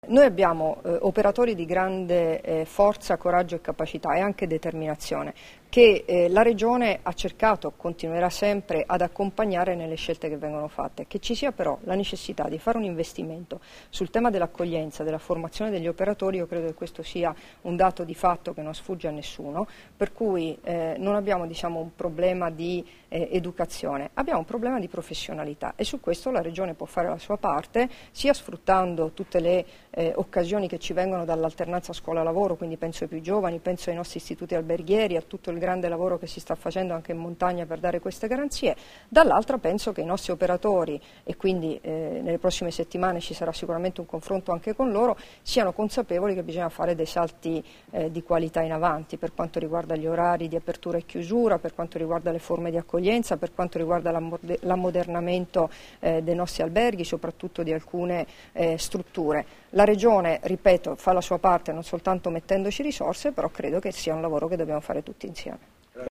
Dichiarazioni di Debora Serracchiani (Formato MP3) [1058KB]
a conclusione della stagione turistica invernale e alla vigilia di quella estiva in FVG, rilasciate a Trieste il 12 aprile 2016